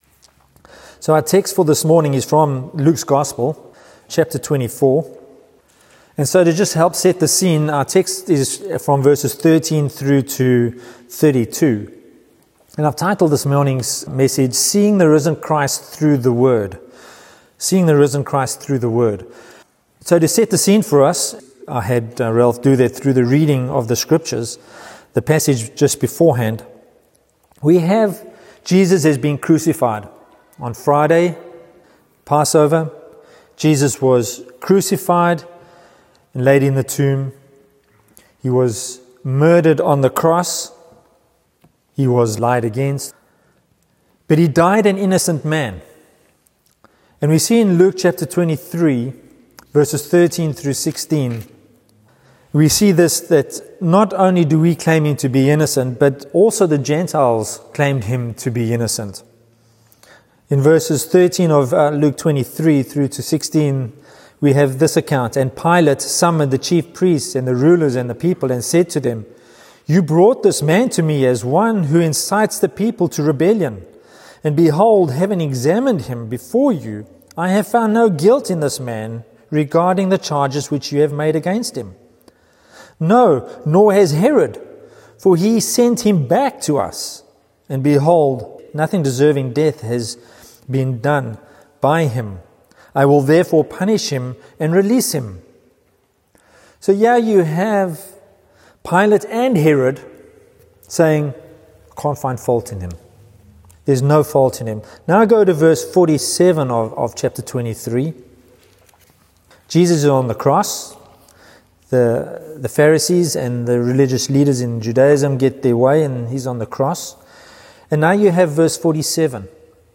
Series: Expository Sermons